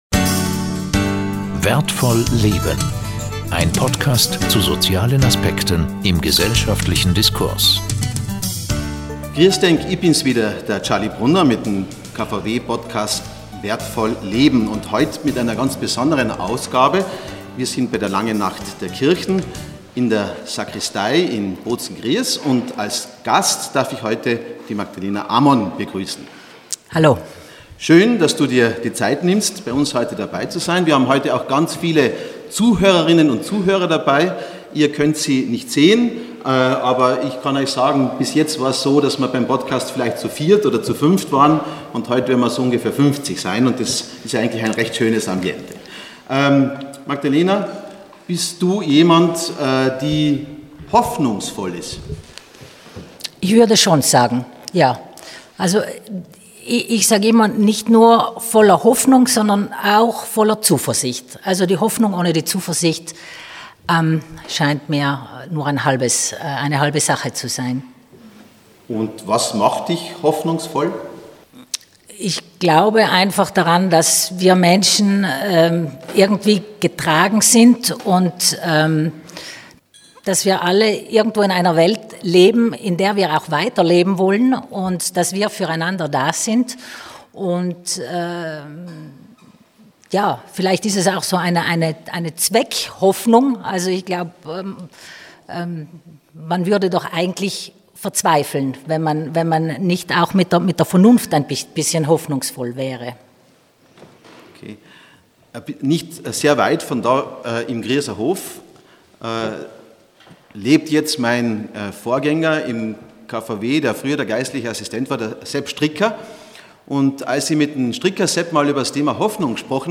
Anlässlich der Langen Nacht der Kirchen aus der Sakristei der Stiftspfarrkirche von Gries